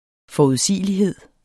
Udtale [ fɒuðˈsiˀəliˌheðˀ ]